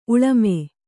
♪ uḷame